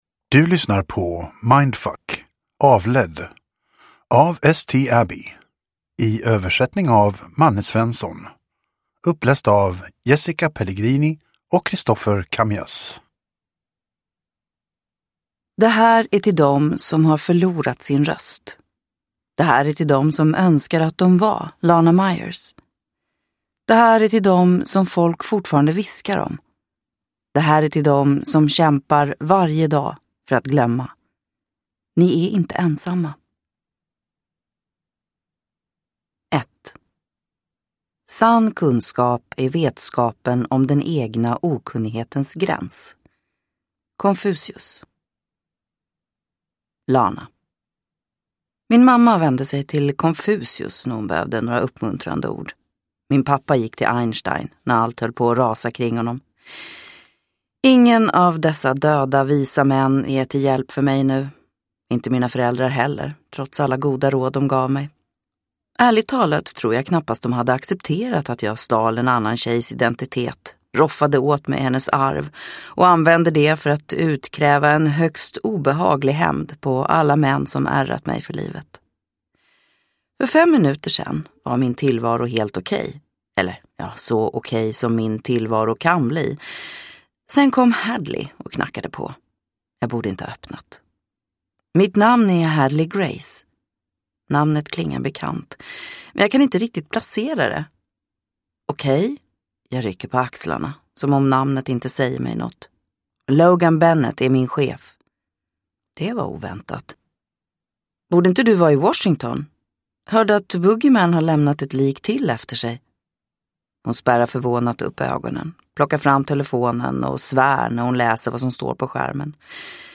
Avledd – Ljudbok